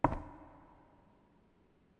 FootstepHandlerWood3.wav